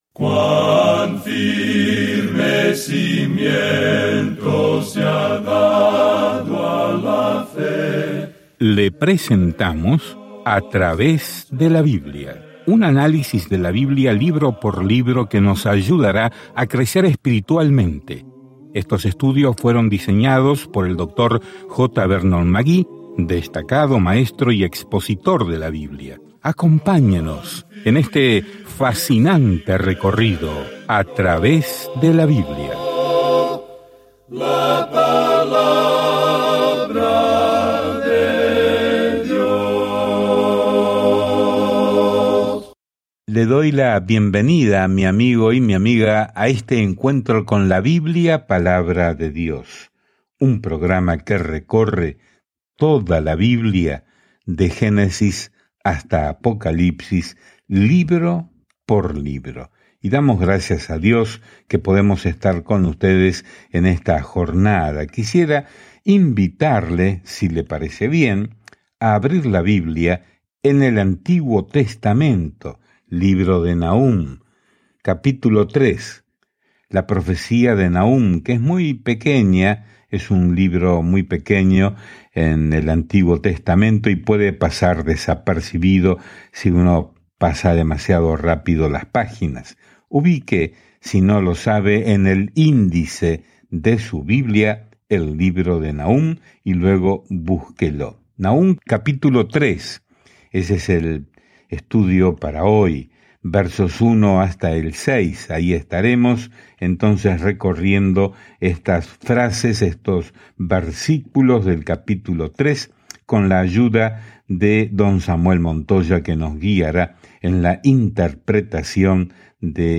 Escrituras NAHUM 3:1-6 Día 6 Comenzar este Plan Día 8 Acerca de este Plan Nahum, cuyo nombre significa consuelo, trae un mensaje de juicio a los enemigos de Dios y trae justicia y esperanza a Israel. Viaje diariamente a través de Nahum mientras escucha el estudio de audio y lee versículos seleccionados de la palabra de Dios.